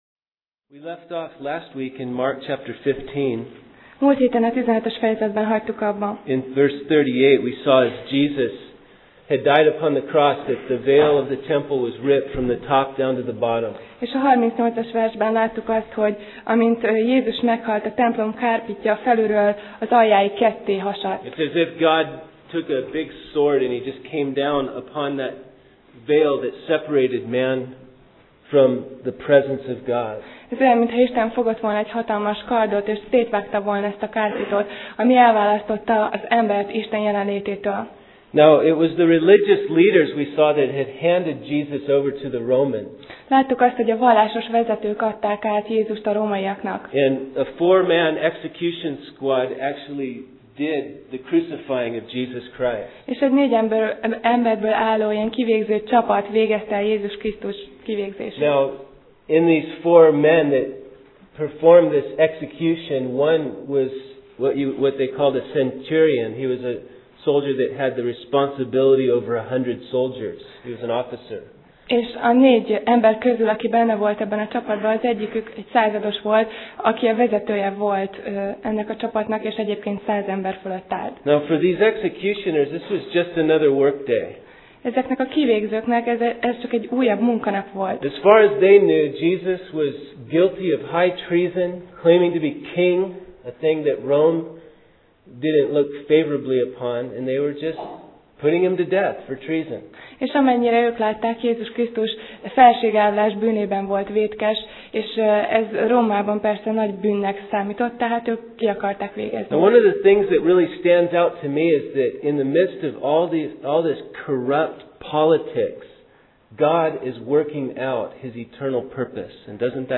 Alkalom: Vasárnap Reggel